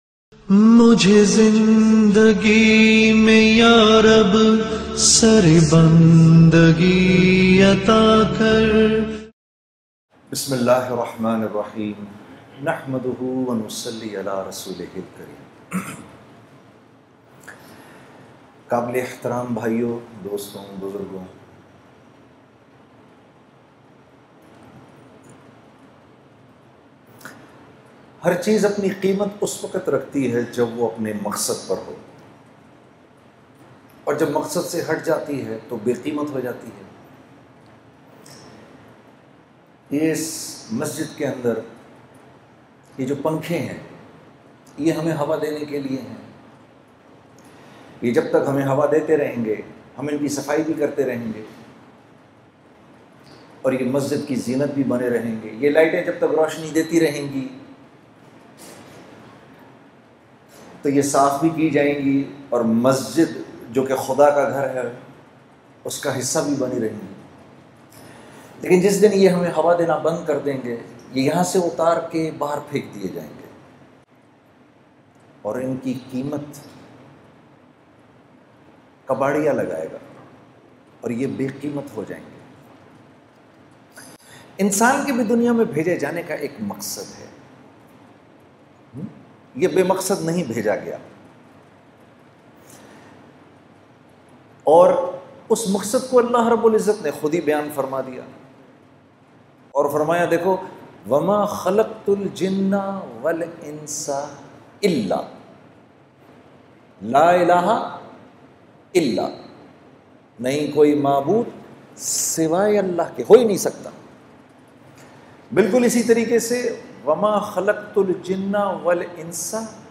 Listen University of lahore bayan by Junaid Jamshed - socioOn
university-of-lahore-bayan.mp3